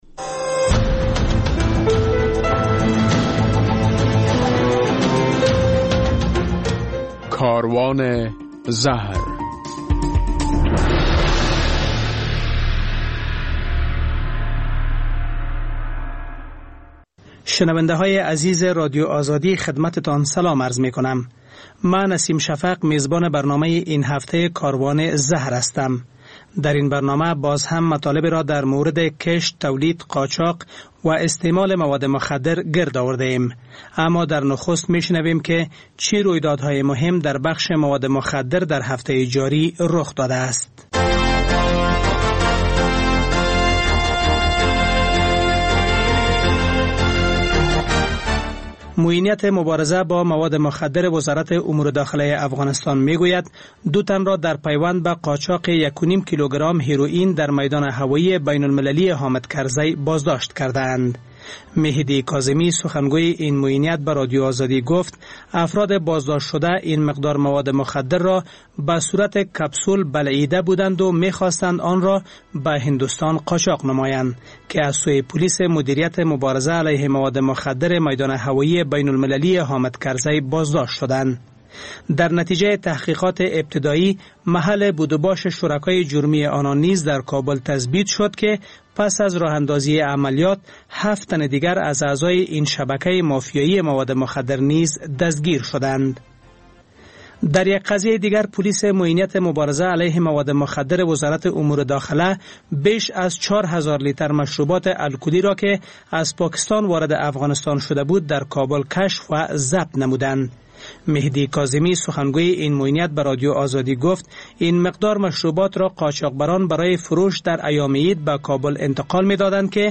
در برنامه این هفته کاروان زهر، در نخست خبرها، بعداً گزارش‌ها، بعد از آن مصاحبه و به تعقیب آن خاطره یک معتاد و ...